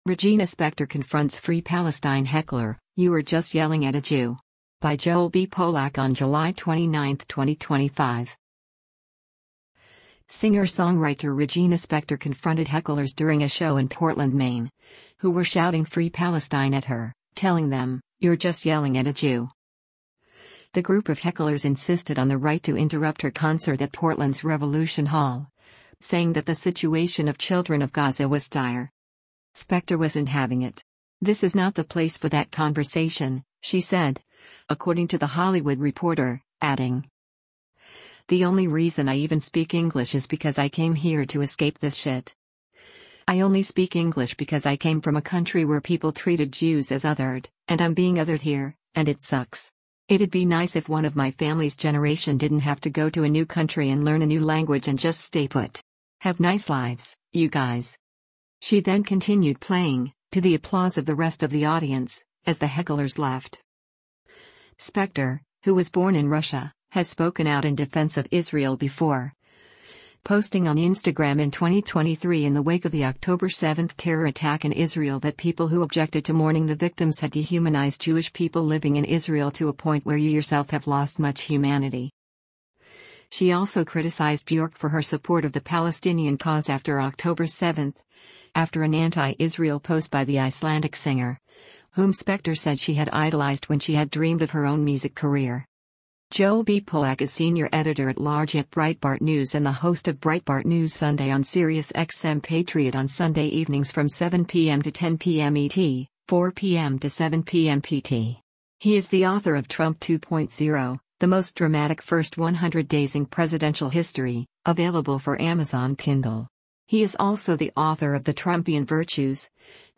Singer-songwriter Regina Spektor confronted hecklers during a show in Portland, Maine, who were shouting “Free Palestine” at her, telling them: “You’re just yelling at a Jew.”
The group of hecklers insisted on the right to interrupt her concert at Portland’s Revolution Hall, saying that the situation of children of Gaza was dire.
She then continued playing, to the applause of the rest of the audience, as the hecklers left.